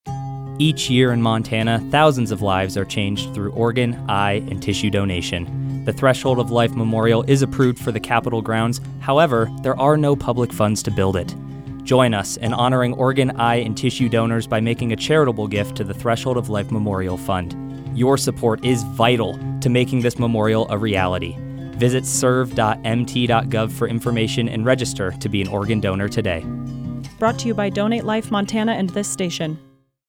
Public Service Announcements
Radio Spots